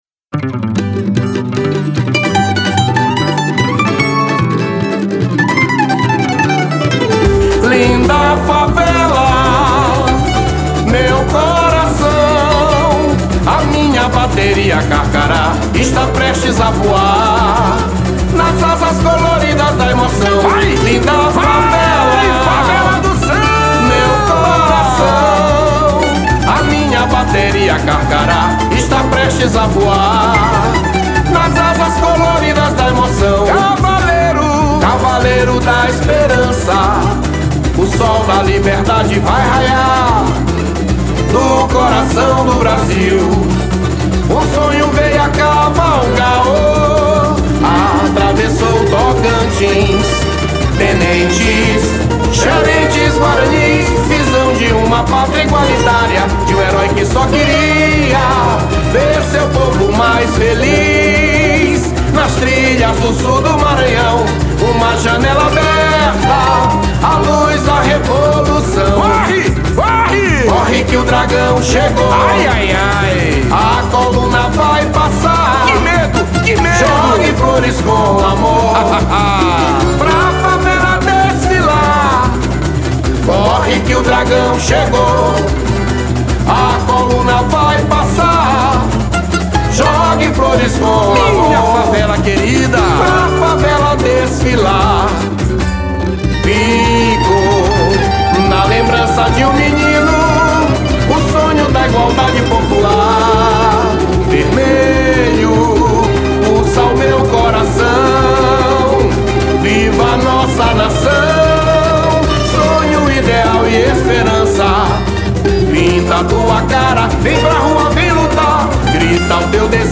samba-enrendo